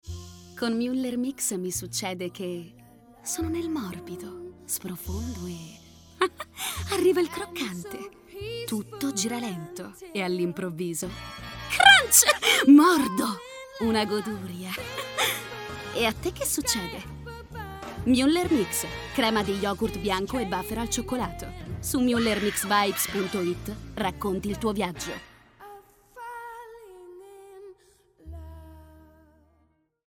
Commercial, Deep, Young, Natural, Distinctive
Corporate
Her voice is basically young, deep and calm, but also energetic, gritty, characterful.